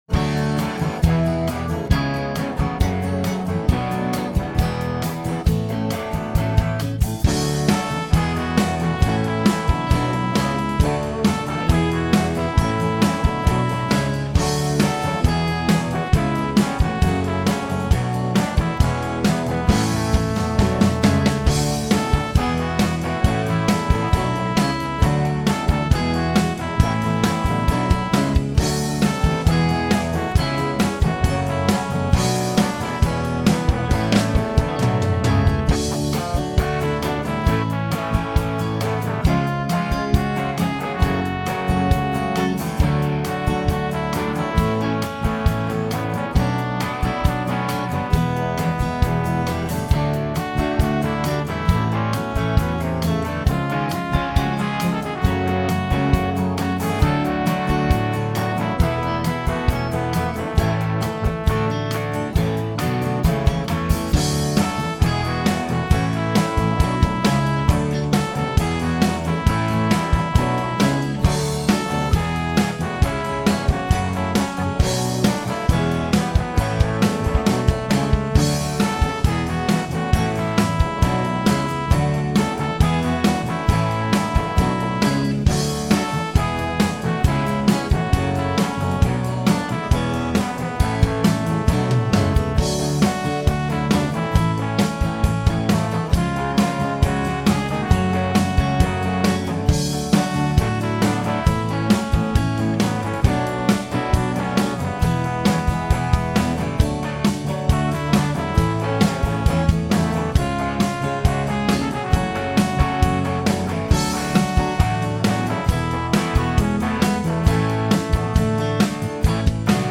up tempo